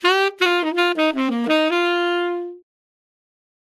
Hall Reverb
Unprocessed (Dry)
Tenor Sax
Echo-Chamber-02-Hall-Reverb-Tenor-Saxophone-Dry.mp3